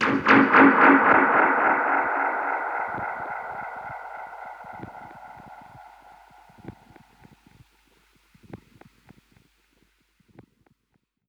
Index of /musicradar/dub-percussion-samples/85bpm
DPFX_PercHit_A_85-04.wav